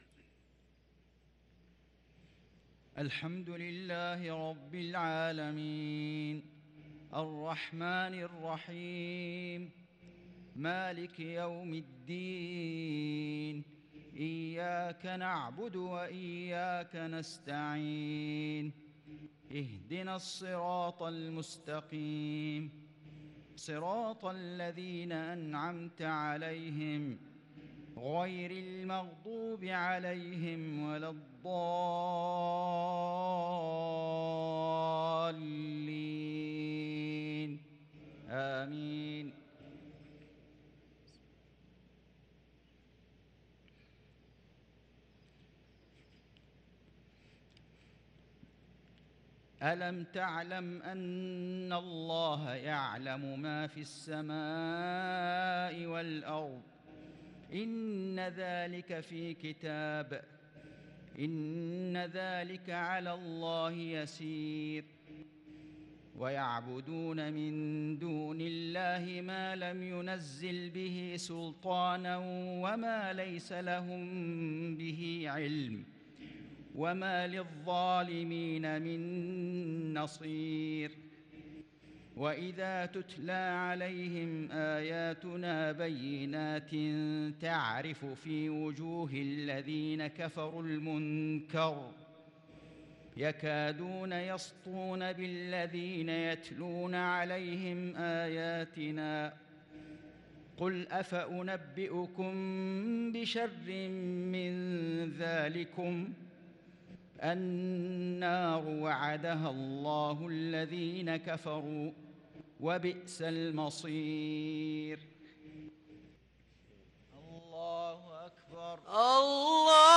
صلاة المغرب للقارئ فيصل غزاوي 18 شوال 1443 هـ